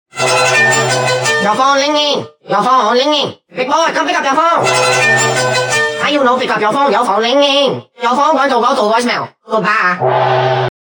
your-phone-linging-made-with-Voicemod-technology-timestrech-1-timestrech-1.01x.mp3